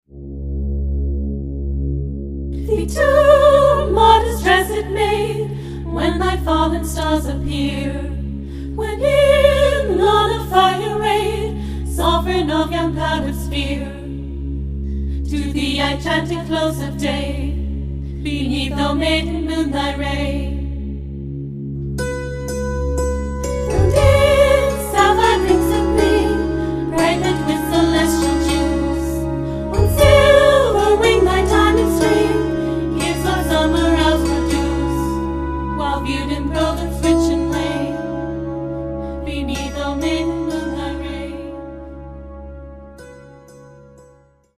neo-classical/ethereal musical project